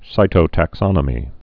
(sītō-tăk-sŏnə-mē)